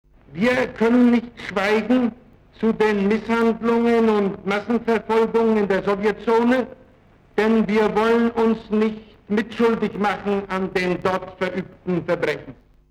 Statement of Willy Brandt at a press conference in Berlin, November 1948